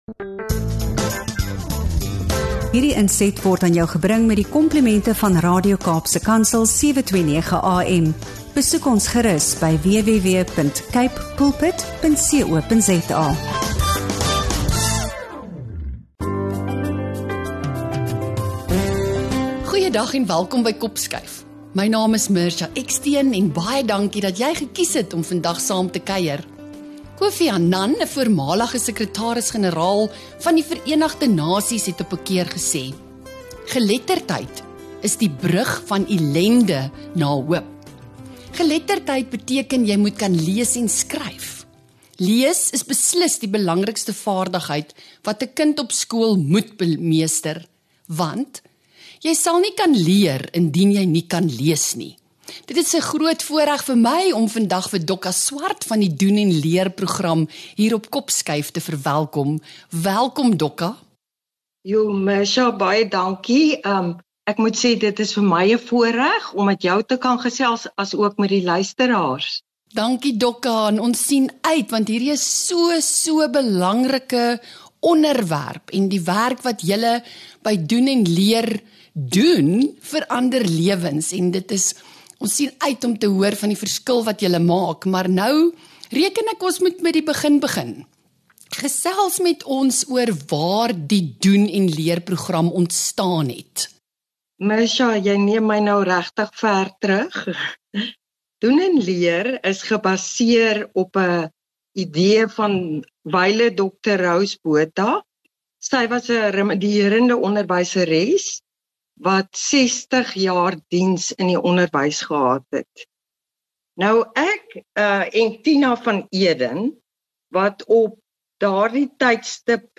Moenie hierdie inspirerende gesprek misloop nie!